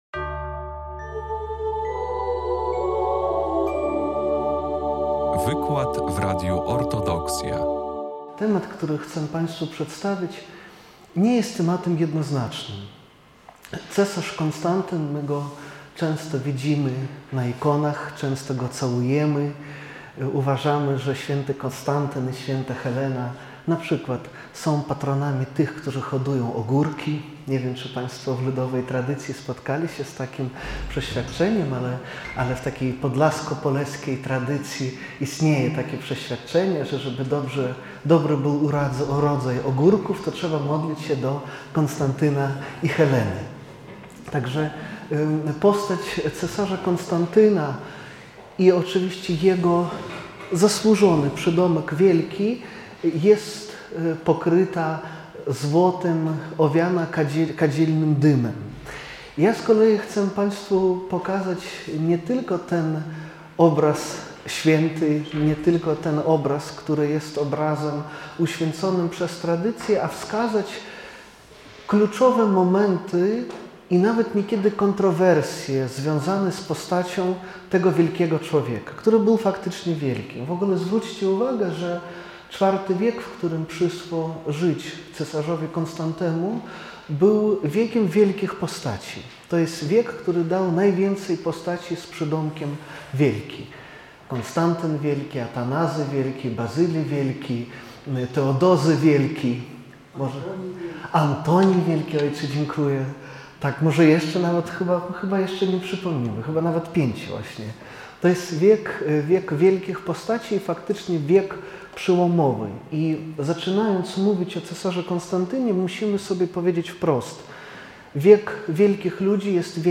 Zapraszamy na ostatni w tym roku akademickim wykład z cyklu Wszechnicy Kultury Prawosławnej wygłoszony 28 kwietnia 2025 w Centrum Kultury Prawosławnej w Białymstoku.